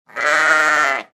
Odinochny-zvuk-barana
• Categoría: Ovejas
• Calidad: Alta